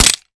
weap_mike9a3_fire_last_plr_mech_02.ogg